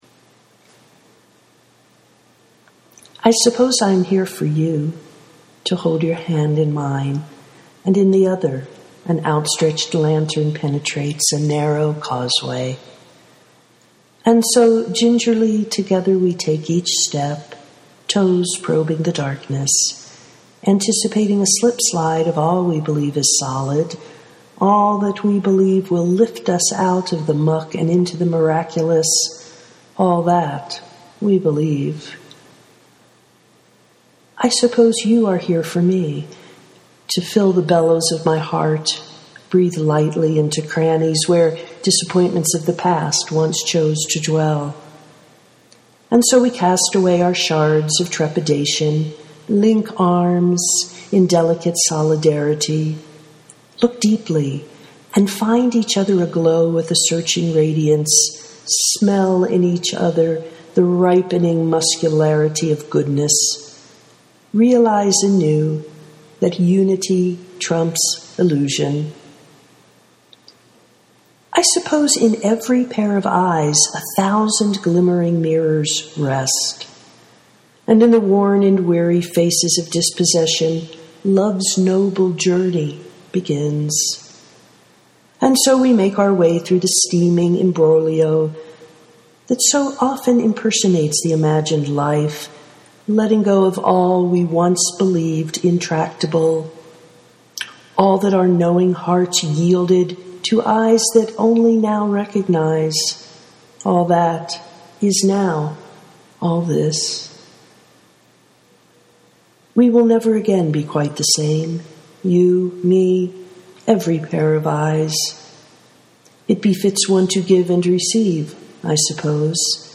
we are here for each other (audio poetry 2:12)